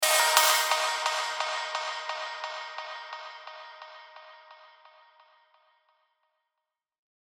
Vocal Sweeps 2 D#
Antidote_Zodiac-Vocal-Sweeps-2-D.mp3